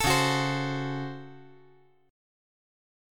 DbM7sus4#5 Chord
Listen to DbM7sus4#5 strummed